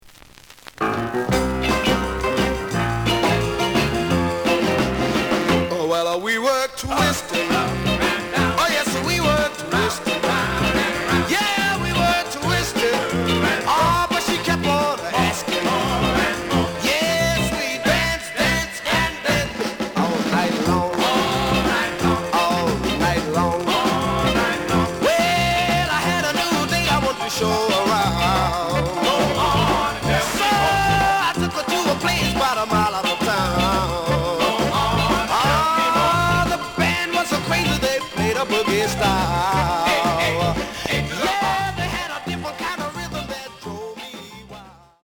The audio sample is recorded from the actual item.
●Genre: Rhythm And Blues / Rock 'n' Roll
Some noise on parts of B side.)